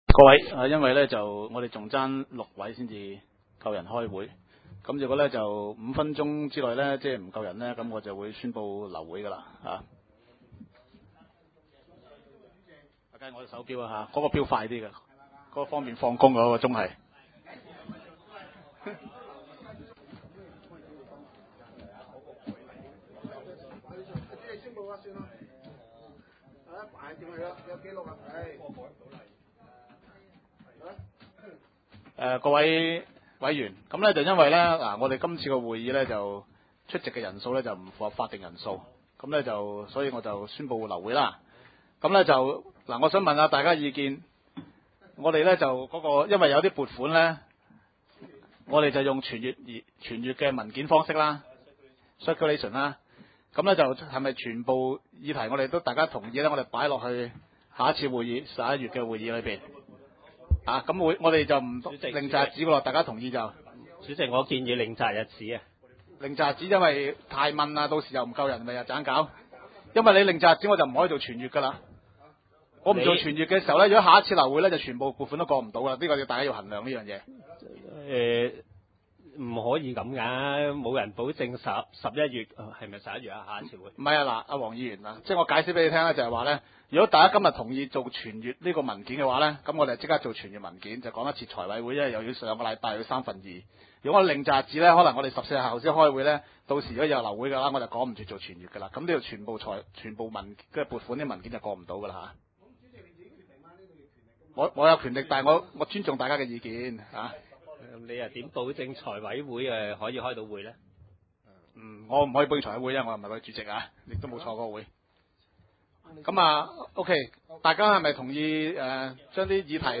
二零零九年度第五次會議
點：元朗橋樂坊二號元朗政府合署十三樓會議廳